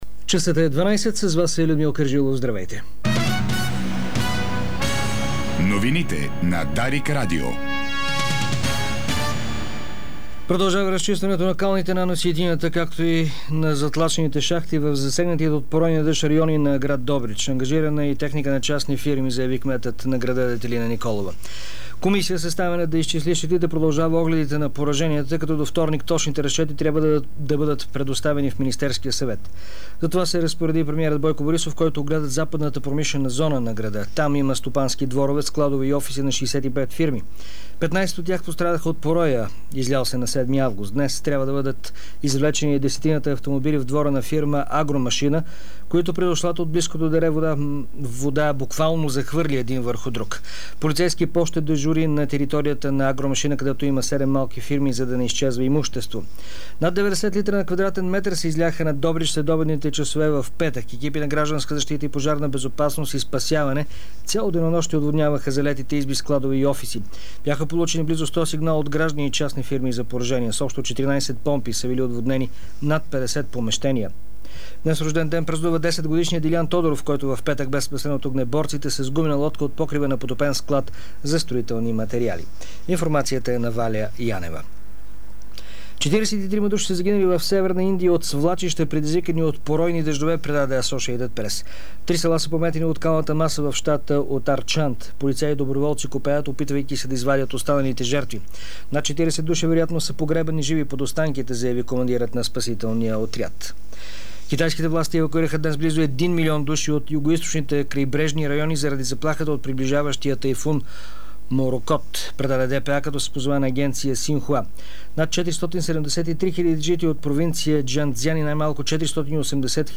Обедна информационна емисия - 09.08.2009